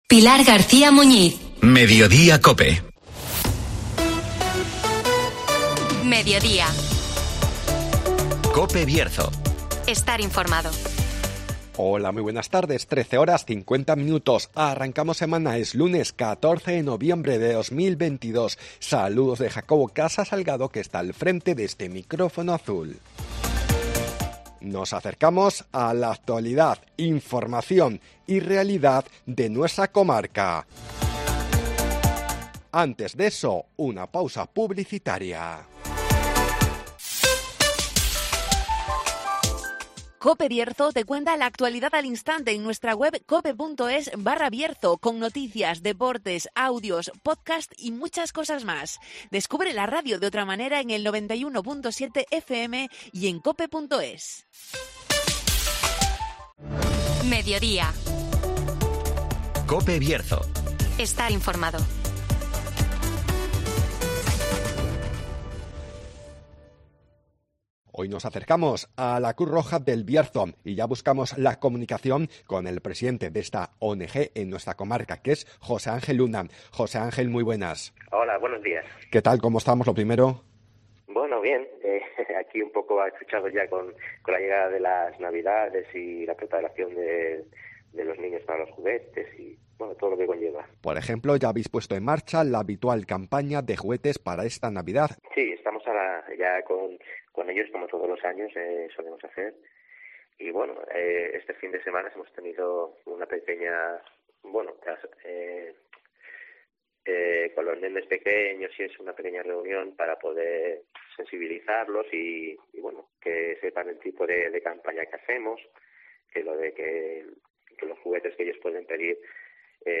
Nos acercamos a la Cruz Roja del Bierzo (Entrevista